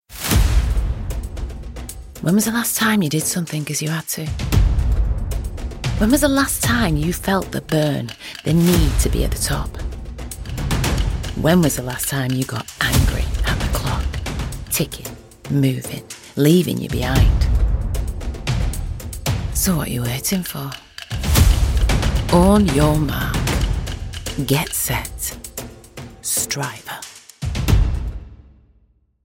south london
Victoria_Ekanoye_SouthLondon.mp3